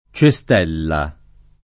DOP: Dizionario di Ortografia e Pronunzia della lingua italiana
Cestello [©eSt$llo] (raro Cestella [